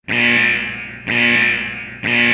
Главная » Рингтоны » SMS рингтоны